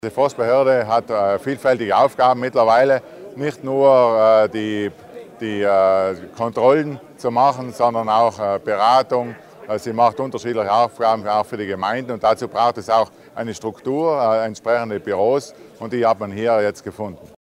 Landesrat Schuler stellt die neue Forststation Tisens vor
Ein Bindeglied zwischen Politik und Landesverwaltung und der Bevölkung: Das sei die Forstbehörde, erklärte der für die Forstwirtschaft zuständige Landesrat Arnold Schuler heute (19. Februar) bei der feierlichen Übergabe der erneuerten und erweiterten Forststation Tisens.